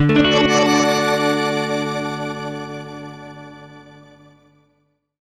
GUITARFX 1-R.wav